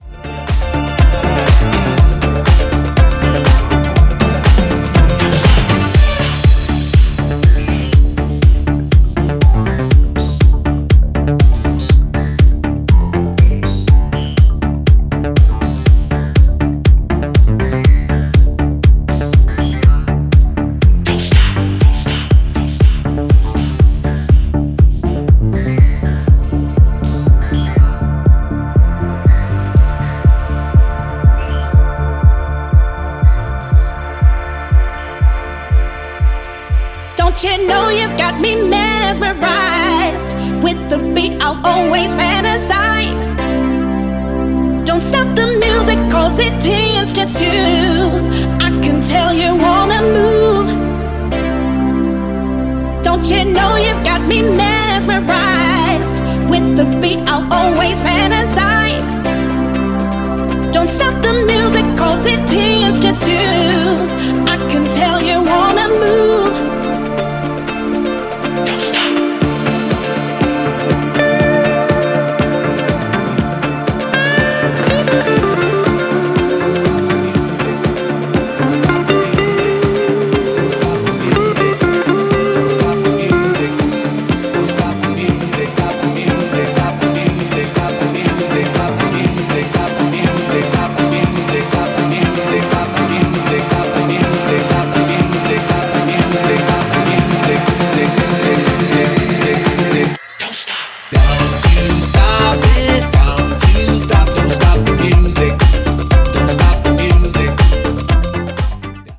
トークボックス使いに、キャッチーな女性ヴォーカルもの。
ジャンル(スタイル) NU DISCO / DANCE